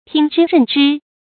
注音：ㄊㄧㄥ ㄓㄧ ㄖㄣˋ ㄓㄧ
聽之任之的讀法